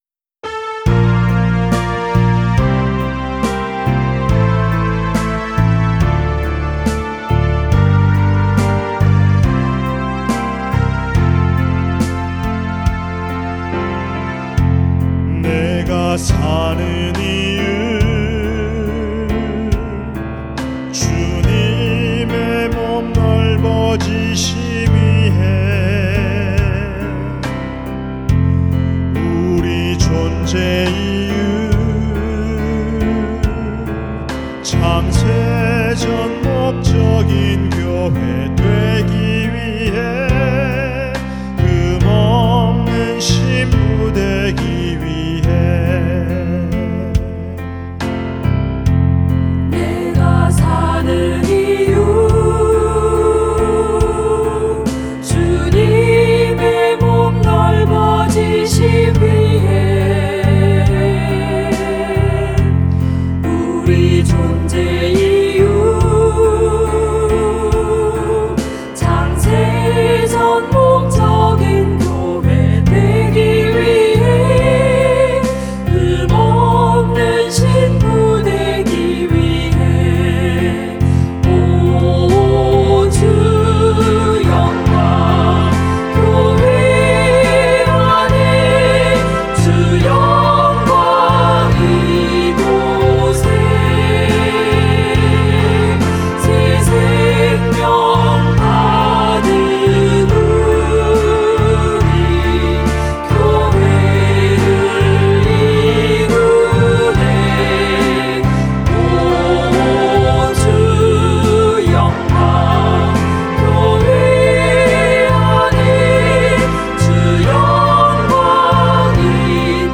45.내가-사는-이유여성합창.mp3